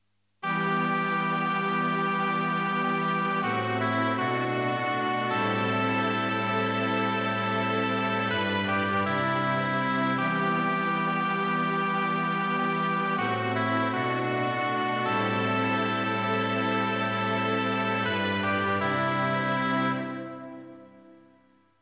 1-S